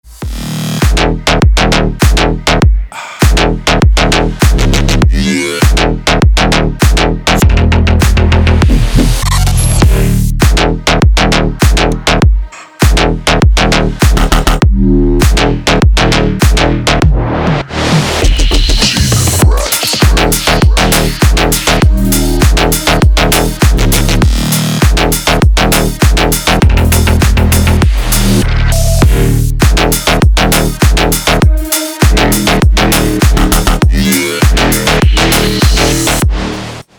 Мощный и громкий рингтон 2024